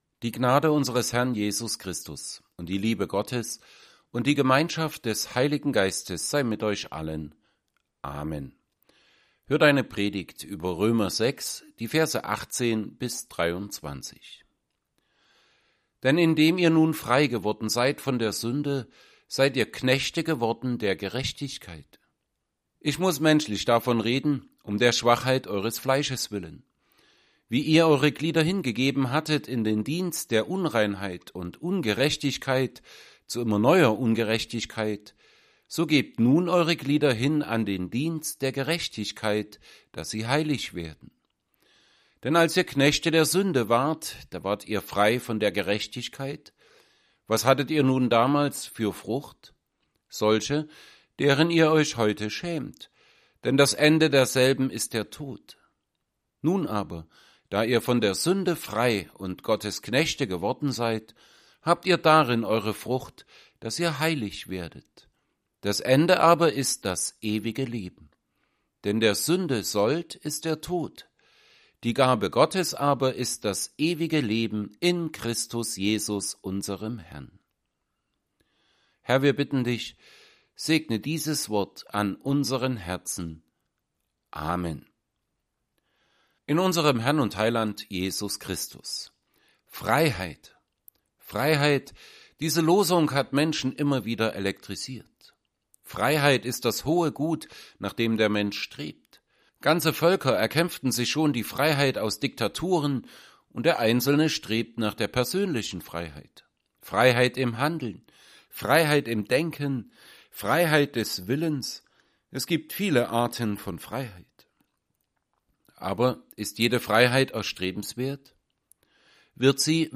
Predigt zu Römer 6,18-23.mp3